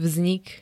vznik [zvn-] m začiatok existencie niečoho: w gednem každem dome aby dobre patrily pri pecach, kominach, kozuboch a kotlikowych peczkach, zdaliss by pri takowych nektere nebespečenstwy skrz ohne swnik nebilo (ZNIEV 1725)
Zvukové nahrávky niektorých slov